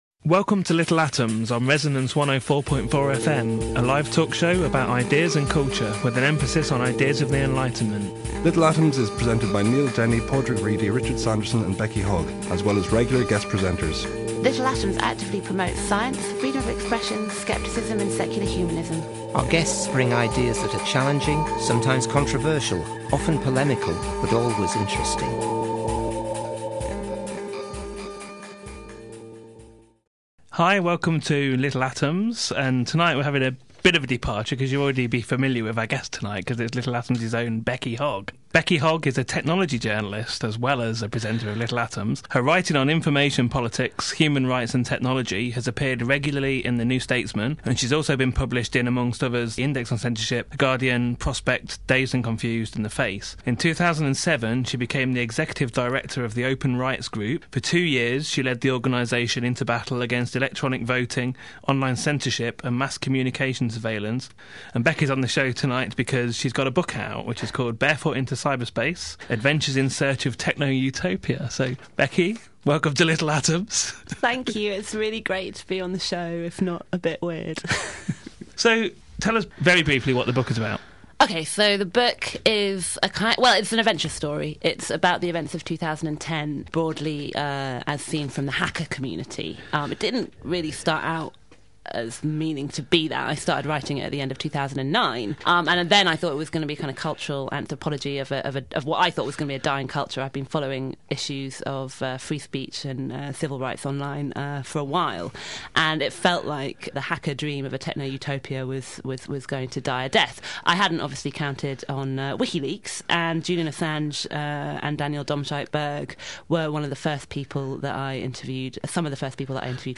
The interview starts at 26:48.